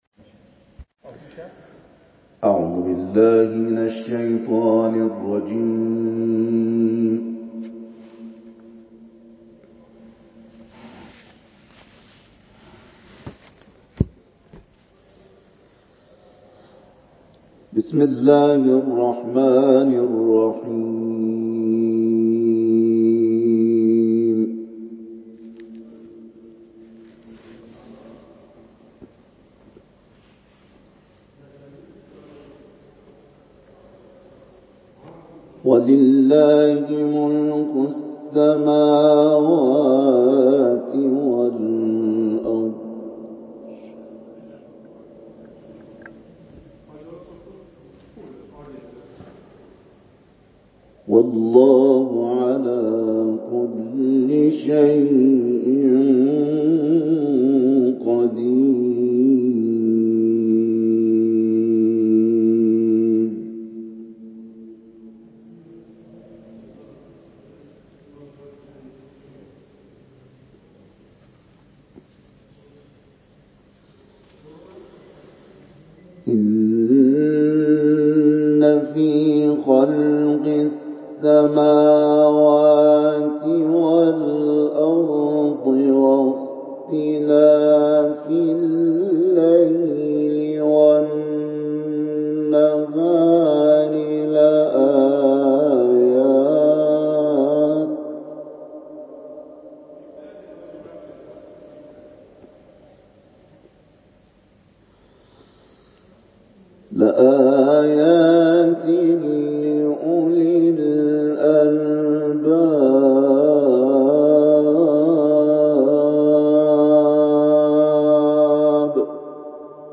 صوت | تلاوت
قاری بین‌المللی قرآن